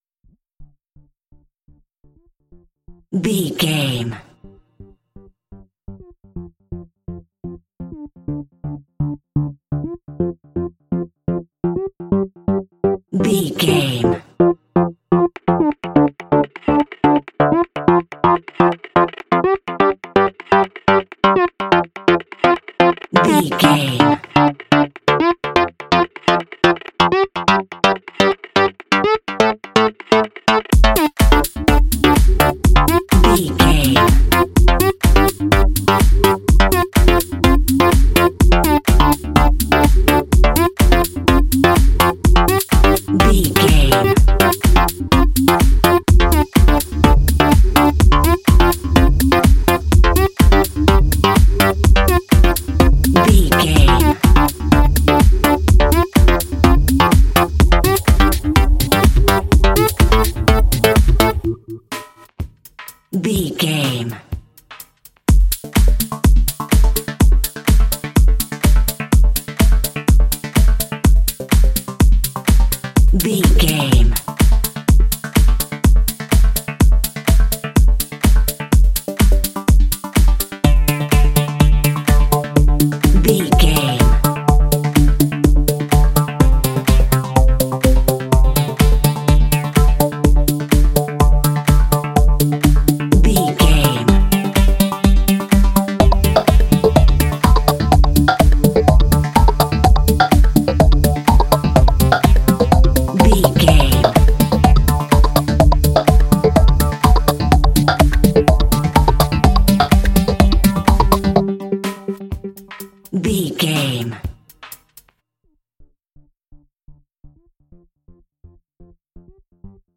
Modern Clubbing Music.
Fast paced
Aeolian/Minor
dark
futuristic
groovy
aggressive
synthesiser
drum machine
electronic
dance
techno
trance
synth leads
synth bass